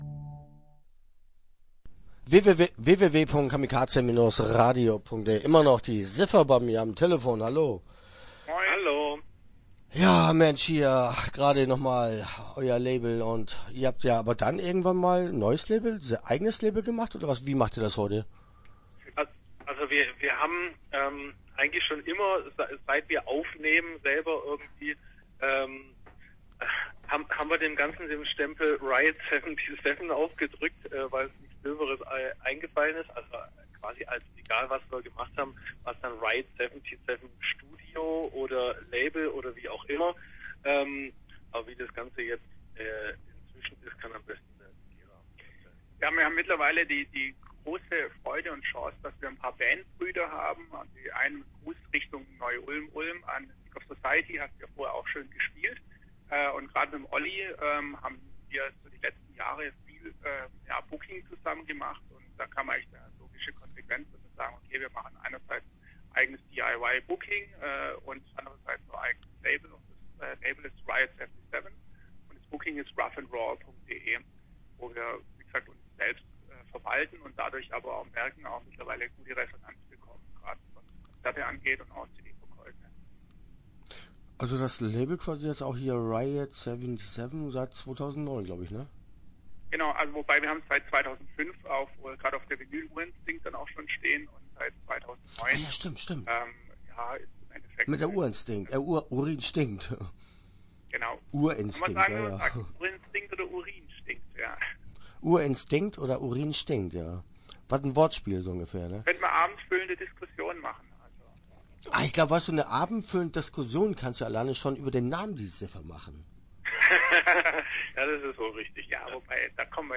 Die Siffer - Interview Teil 1 (13:04)